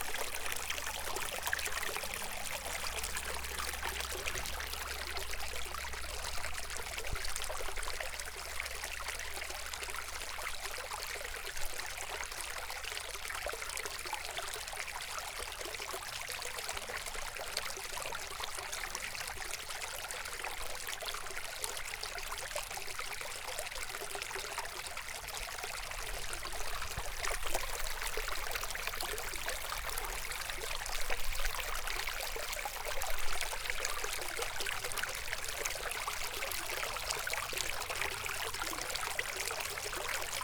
CreekFlow.ogg